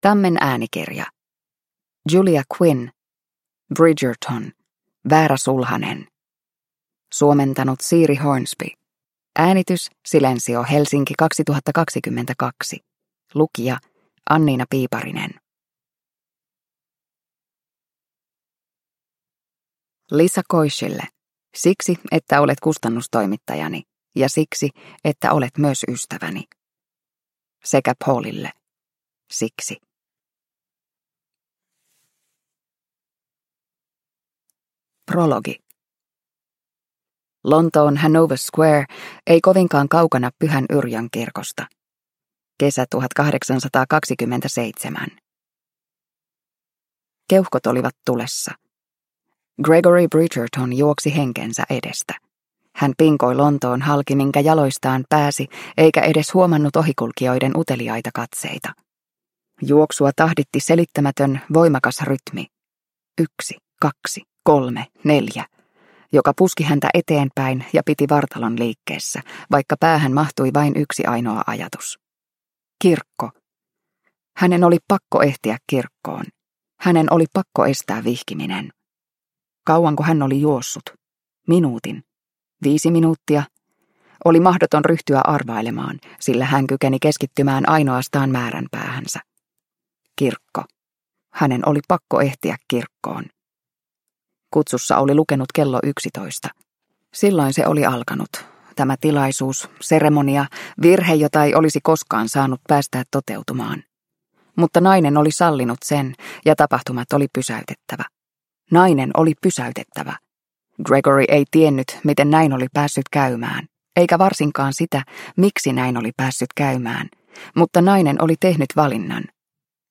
Bridgerton: Väärä sulhanen – Ljudbok – Laddas ner